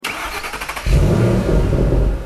enginestart.mp3